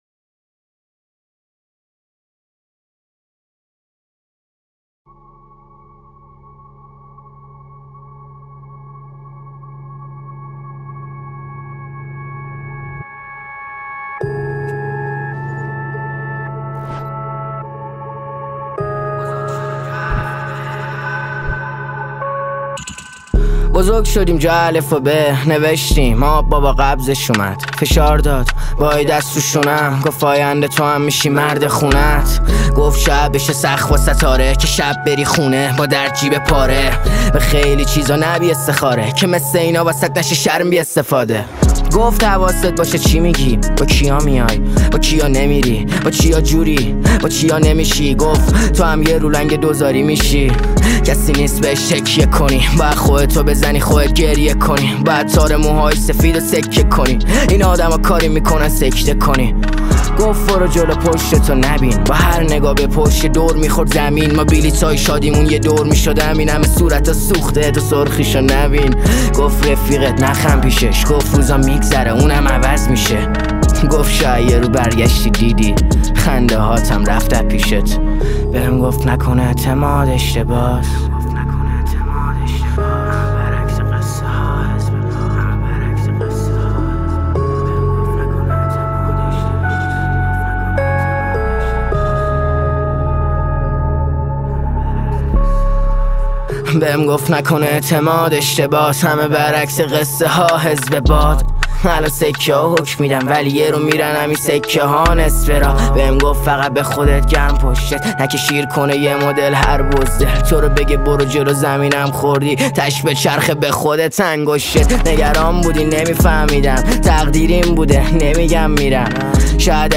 رپ